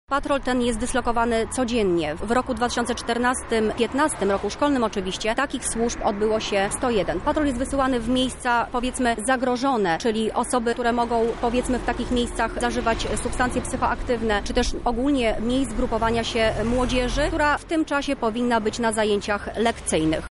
O tym jak działa patrol mówi